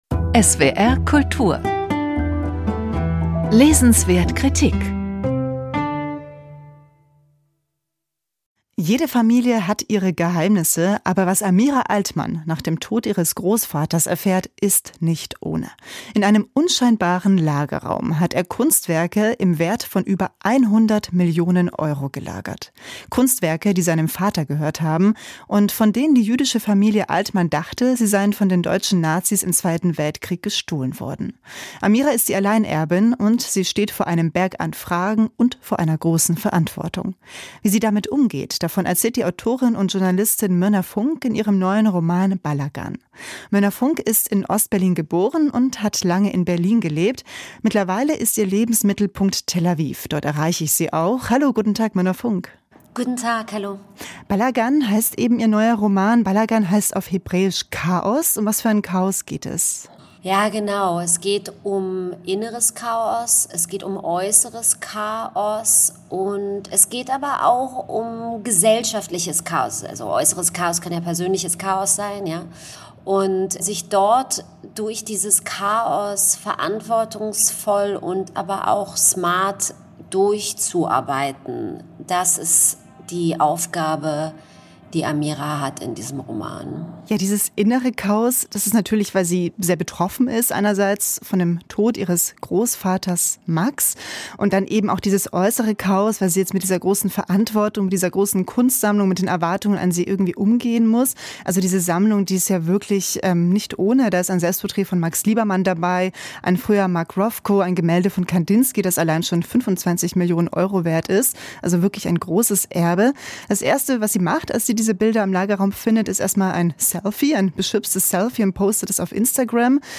im Gespräch mit Mirna Funk